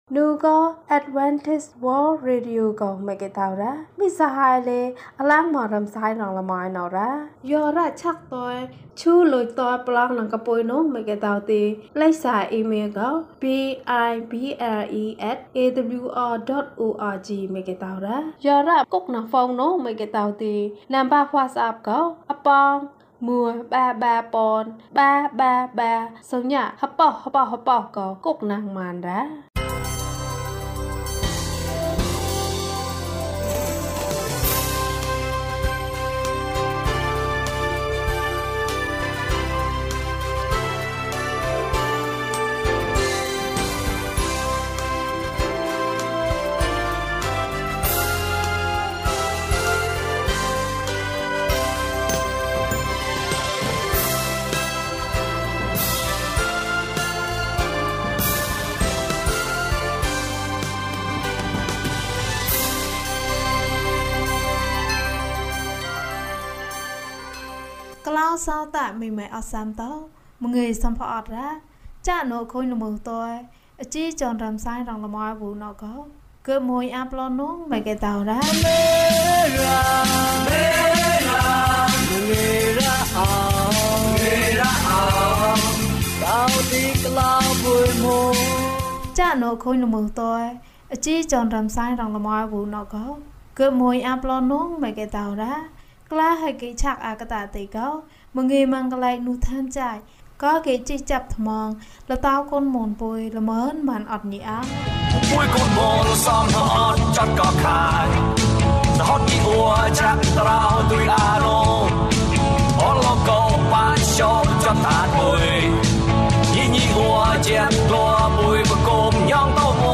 သမ္မာကျမ်းစာဇာတ်လမ်း။ အပိုင်း၂ ကျန်းမာခြင်းအကြောင်းအရာ။ ဓမ္မသီချင်း။ တရားဒေသနာ။